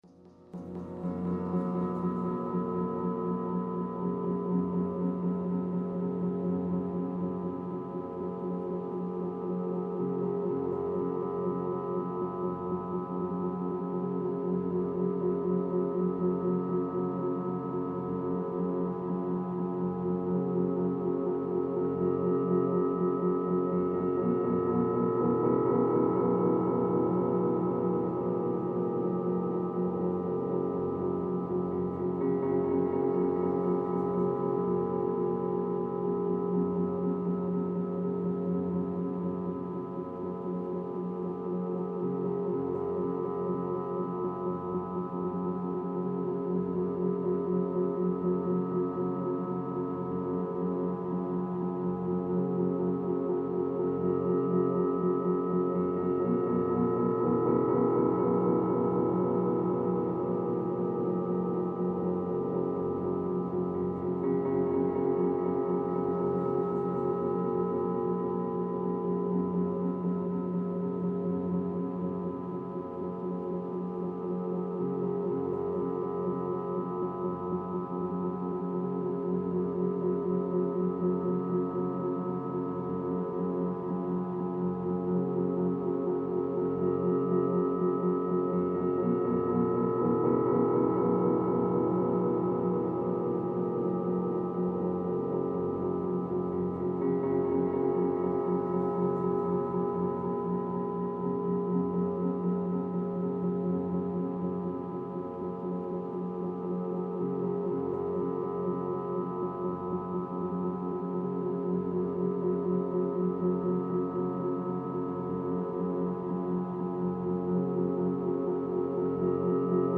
Here is a mix from my Antibes recordings.
piano-.mp3